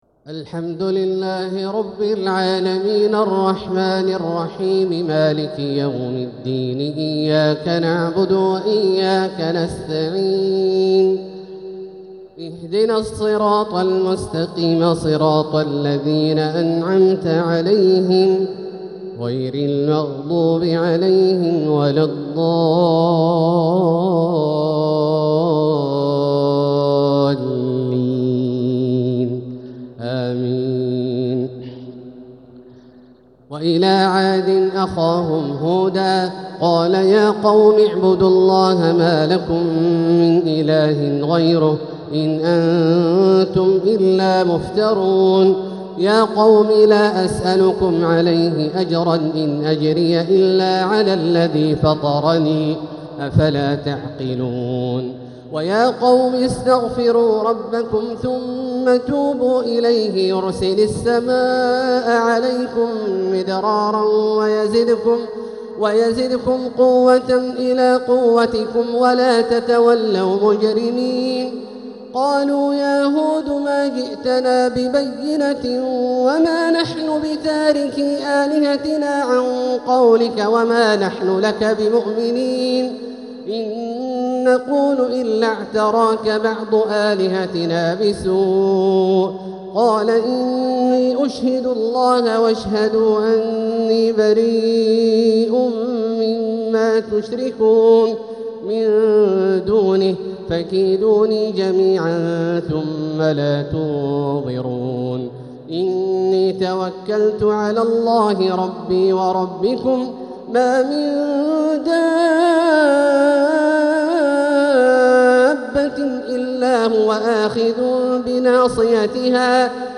تراويح ليلة 16 رمضان 1446هـ من سورة هود (50-109) | Taraweeh 16th Ramadan 1446H Surat Hud > تراويح الحرم المكي عام 1446 🕋 > التراويح - تلاوات الحرمين